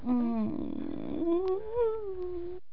Descarga de Sonidos mp3 Gratis: gimiendo 1.
descargar sonido mp3 gimiendo 1